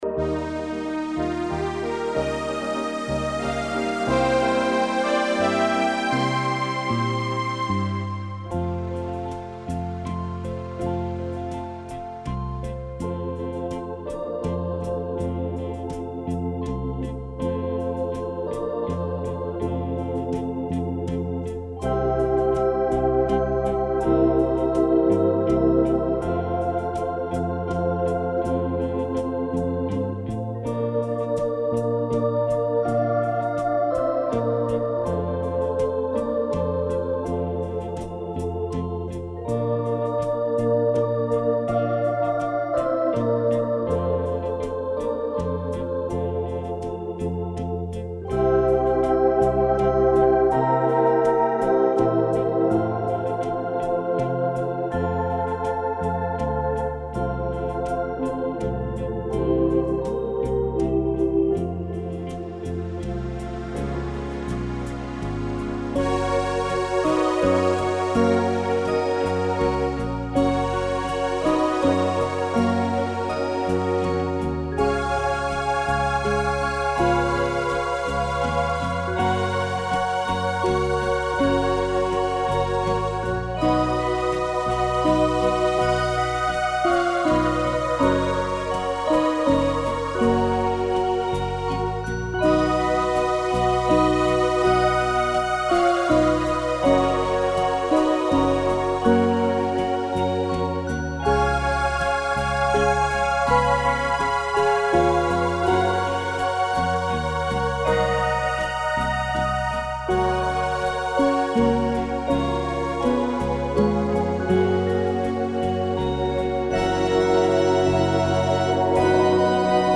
Genre:Instrumental